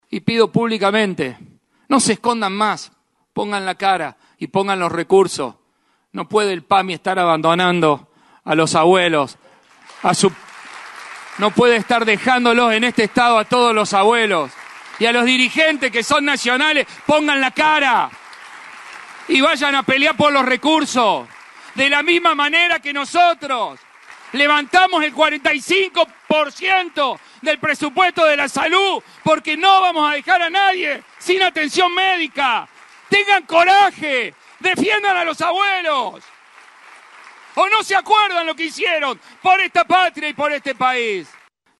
Audio: Martín Llaryora, Gobernador de Córdoba.
En un encendido discurso, el Gobernador de Córdoba denunció el estado de «abandono» en el que se encuentran los jubilados y destacó el esfuerzo provincial para sostener el sistema sanitario ante el recorte de fondos nacionales.